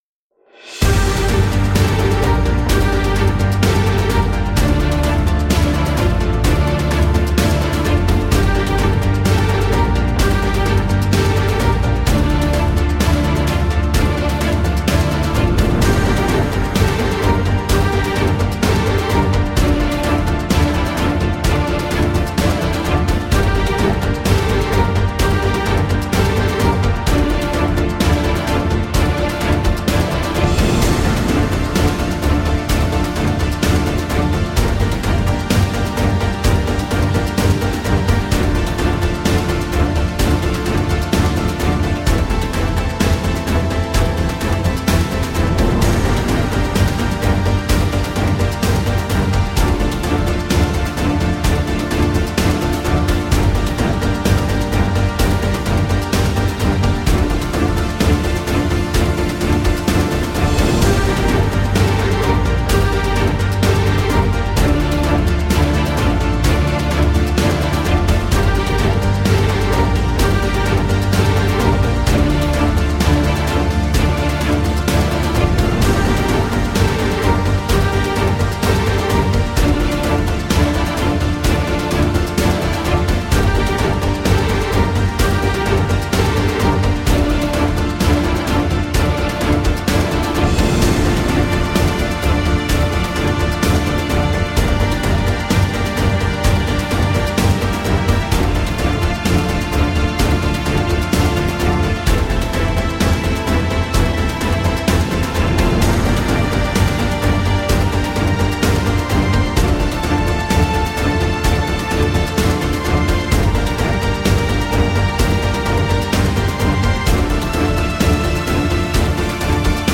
Это в стиле экшен